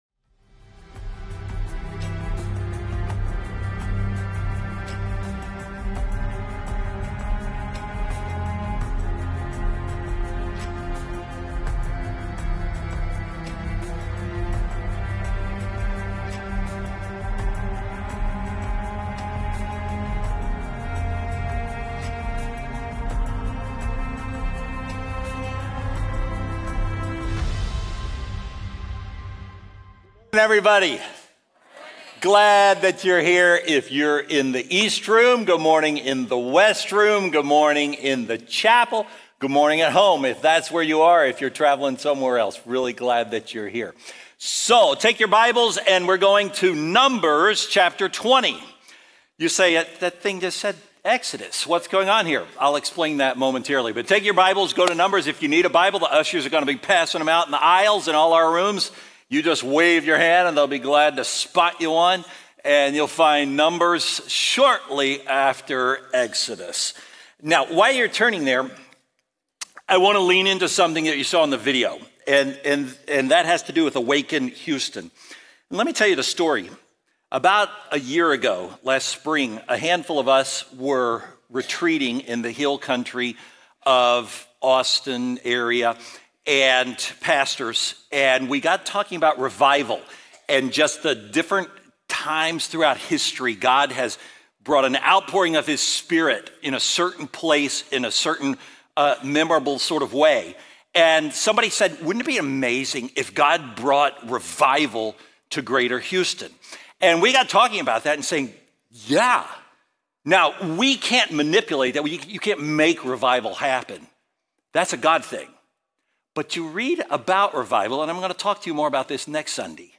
Faithbridge Sermons